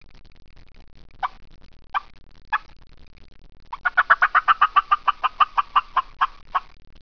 Fly Down Crackle
Used in the early morning to make a gobbler believe a hen has just landed at your location.
flydowncackle2.wav